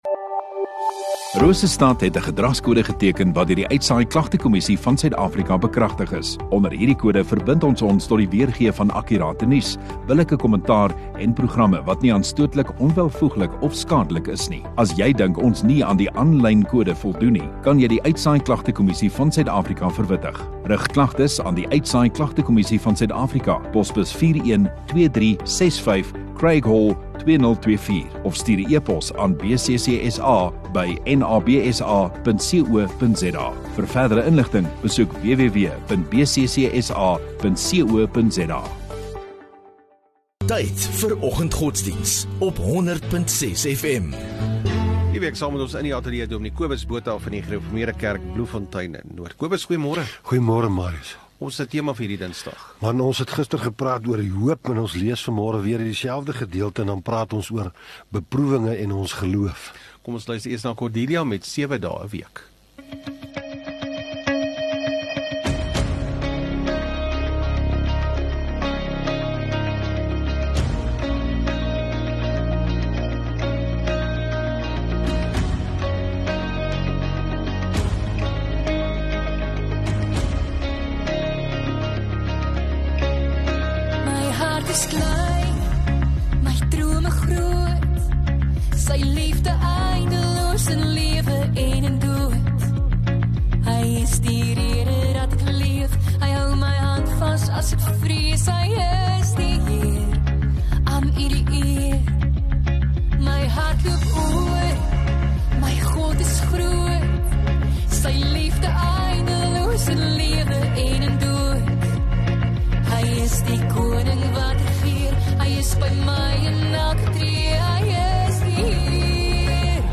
1 Oct Dinsdag oggenddiens